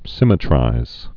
(sĭmĭ-trīz)